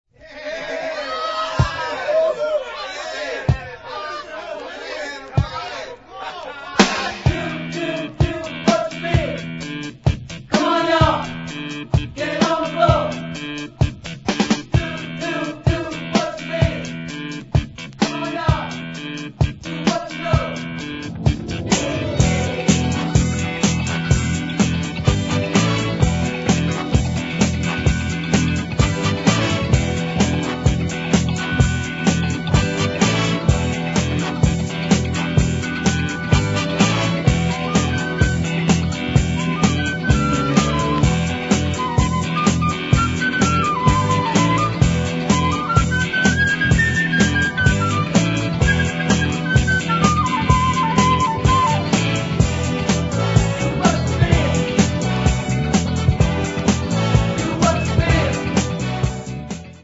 Top-drawer funky stuff
Look for insistent strings and a melody that won't let up.